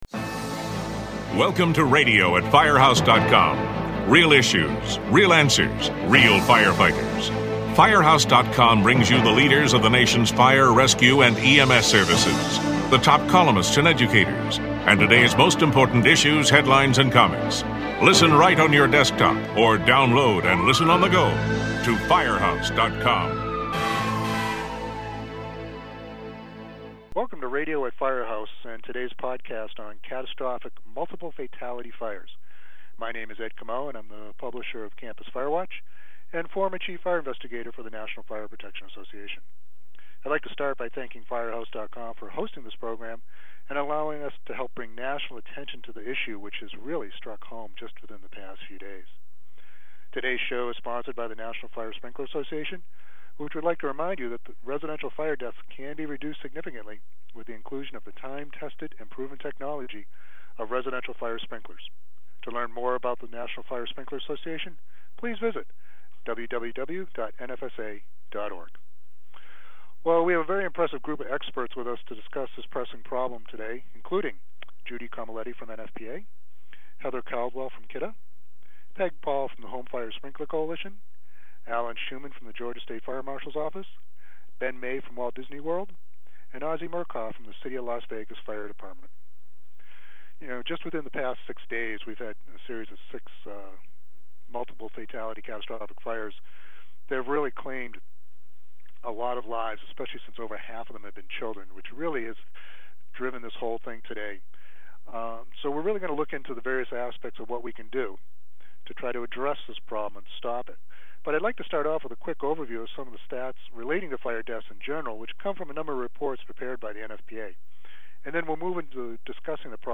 What do we need to do differently to reach people? This podcast includes experts who discuss the importance of prevention, detection and suppression and some time-tested and new and creative ideas that can be used in reaching the different demographics that make up communities across the country.